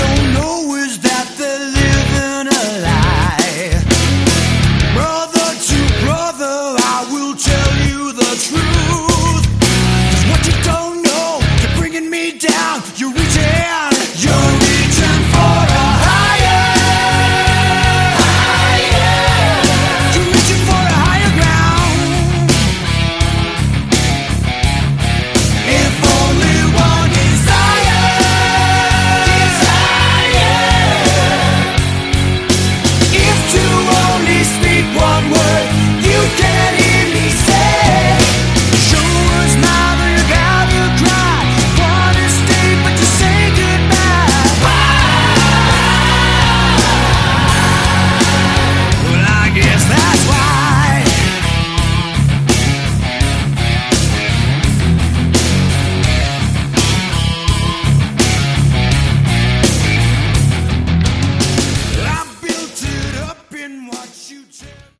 Category: Melodic Hard Rock
lead vocals, guitar
guitar, backing vocals
bass